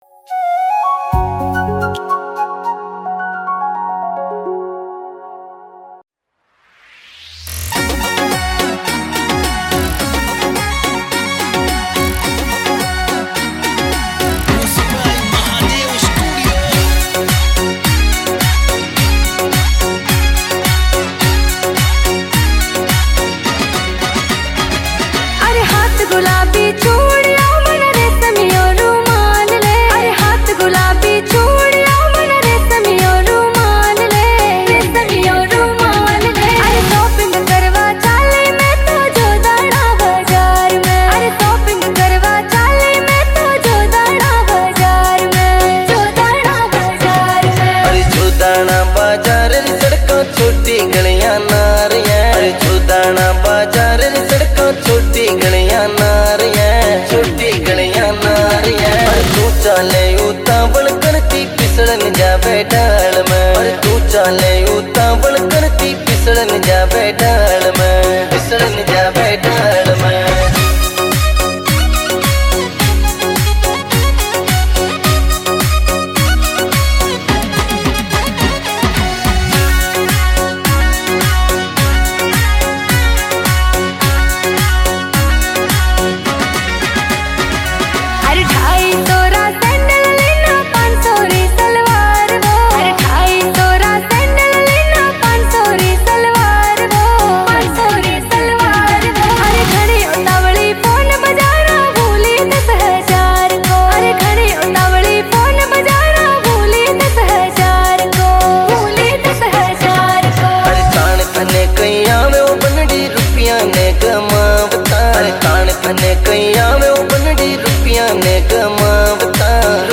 Category: Rajasthani